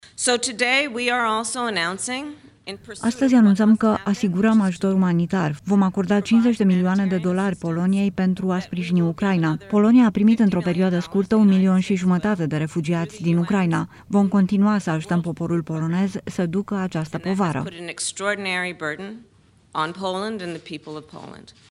Totodată, 50 de milioane de dolari vor ajunge în Polonia, pentru a o ajuta să facă față crizei umanitare creată de valul de refugiați din Ucraina, a mai spus Kalama Harris.